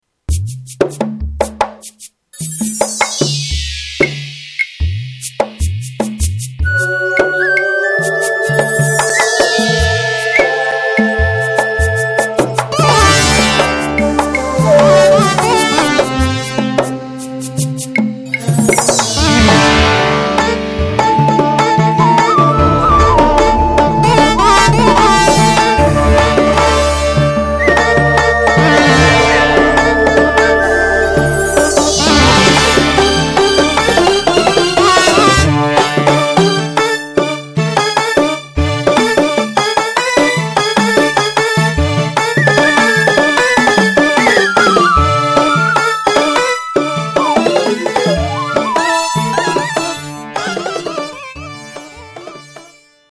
Tags: Kurzweil K2500 Kurzweil K2500 clips Kurzweil K2500 sounds Kurzweil Synthesizer